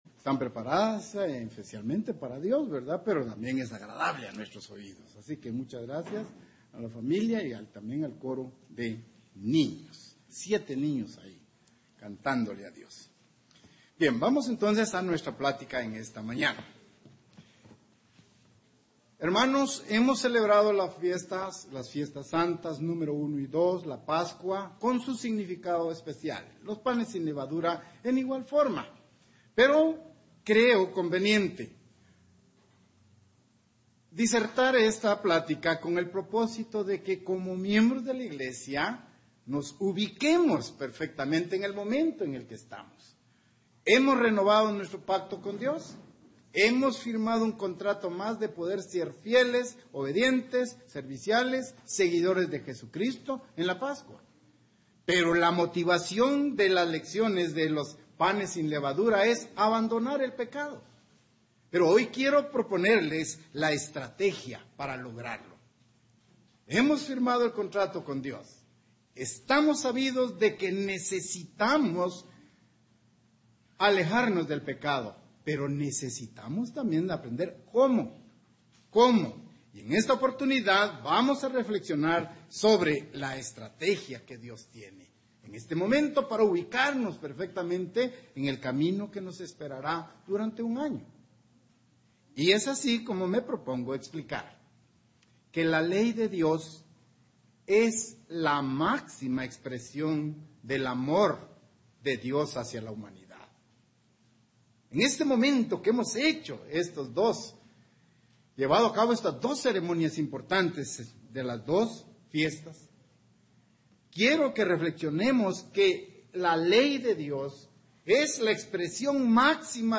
Las Fiestas Santas de Dios nos dan la oportunidad de reflexionar sobre lo que significan la Ley y el Amor. Mensaje entregado el 14 de abril de 2018.
Given in Ciudad de Guatemala